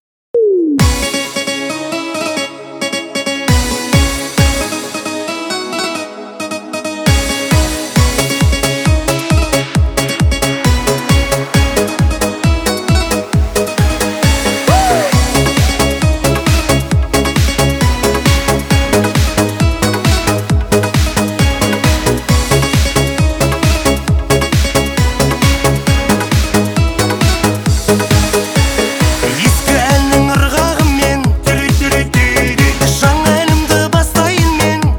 Жанр: Танцевальные / Украинские
# Dance